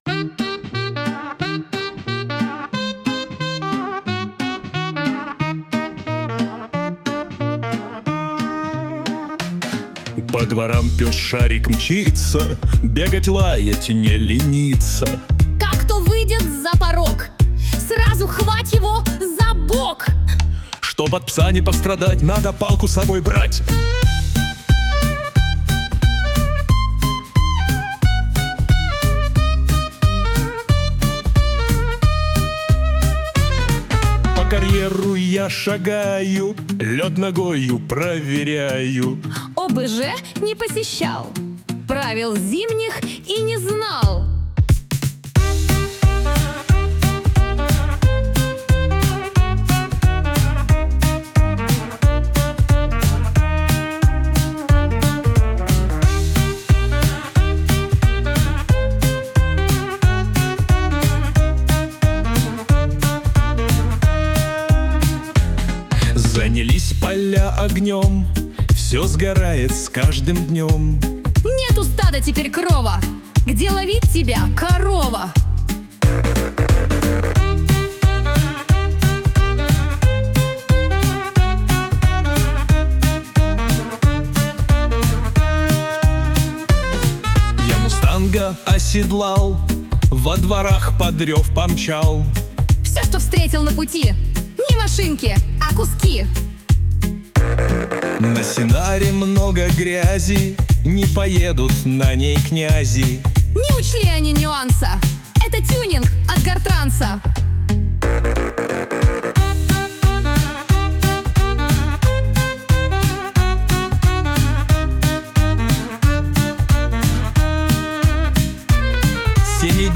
— музыкальный дайджест новостей недели в Калининграде (видео)